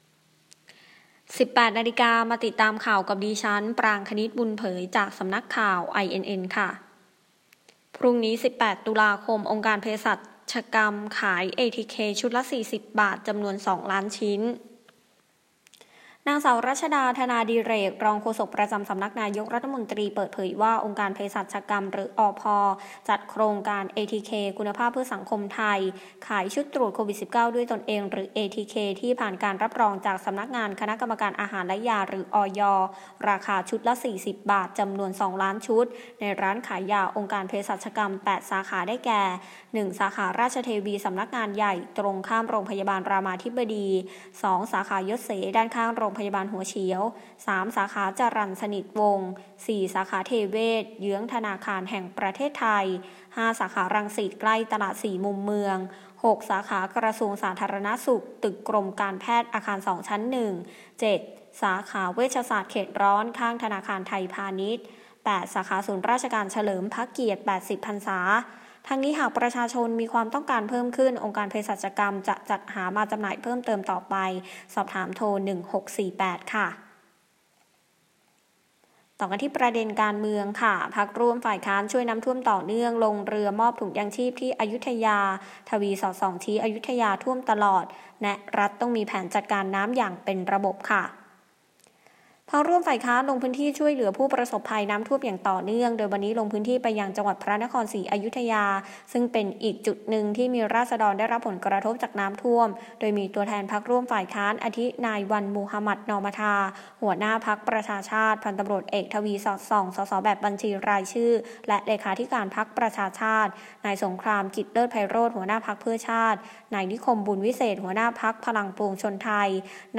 คลิปข่าวต้นชั่วโมง
ข่าวต้นชั่วโมง 18.00 น.